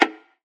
DDW4 PERC 3.wav